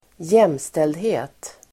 Uttal: [²j'em:steldhe:t]